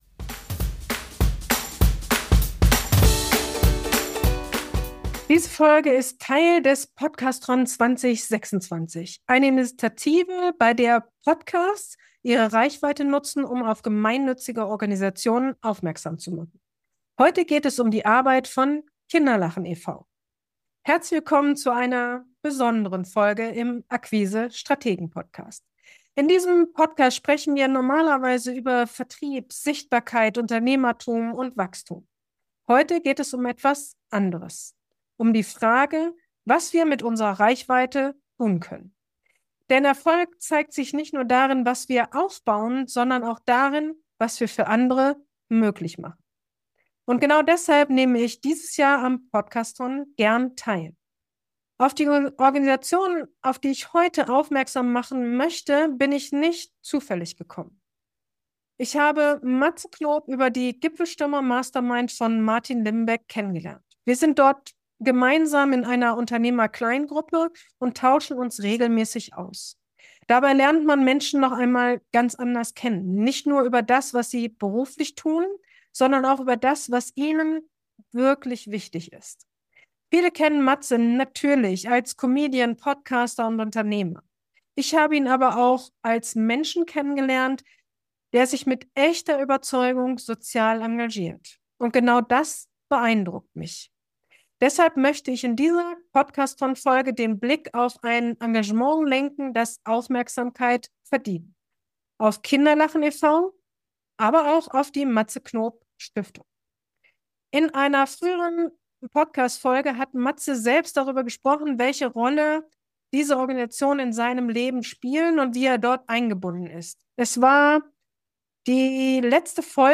Mit einem kurzen Einspieler von Matze Knop sprechen wir über soziales Engagement und darüber, warum Erfolg nicht nur bedeutet, etwas aufzubauen – sondern auch, etwas zurückzugeben. Der kurze Einspieler von Matze Knop in dieser Folge stammt aus einem früheren Gespräch mit ihm.